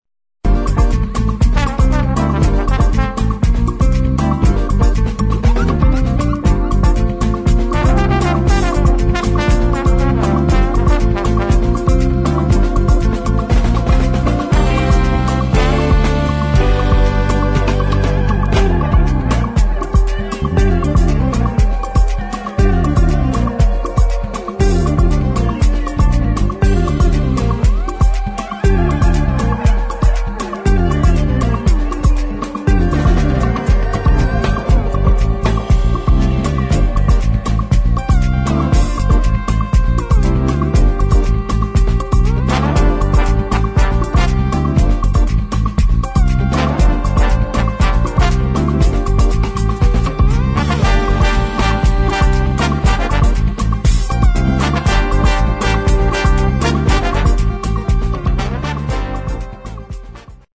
[ DISCO | JAZZ | FUNK ]